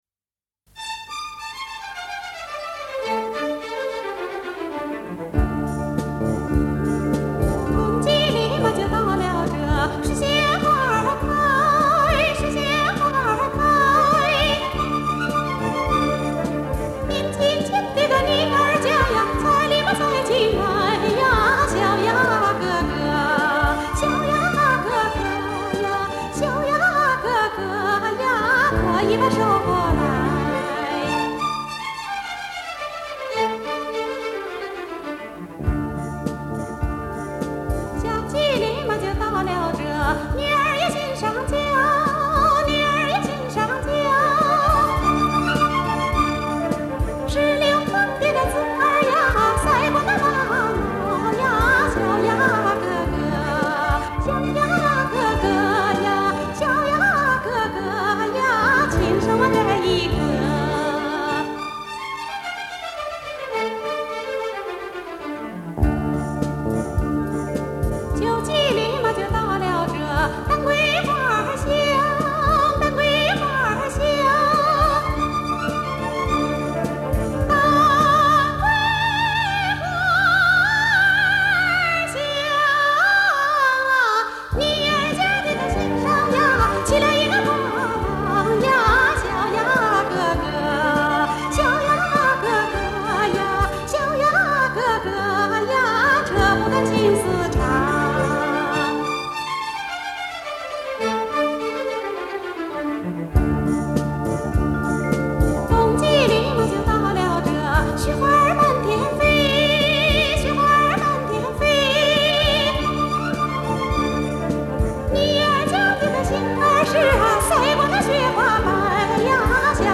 西北花儿